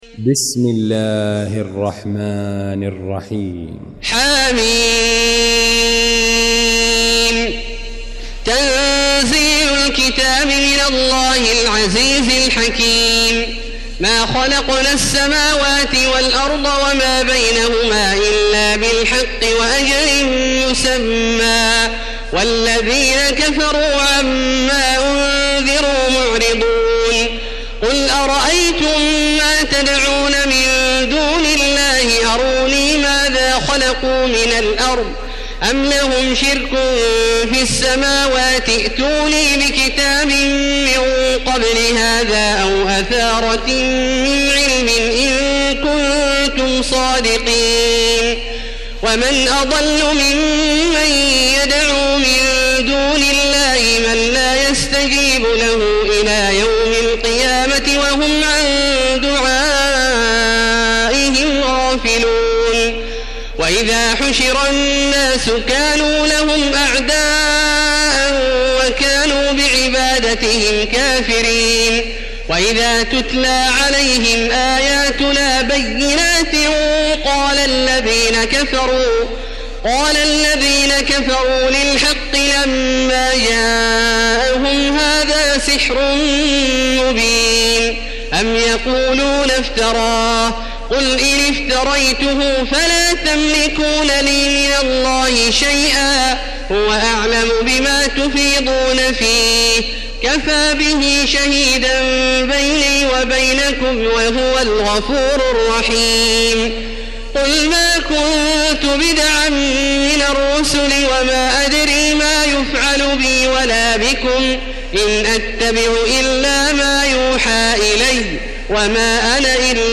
المكان: المسجد الحرام الشيخ: فضيلة الشيخ عبدالله الجهني فضيلة الشيخ عبدالله الجهني الأحقاف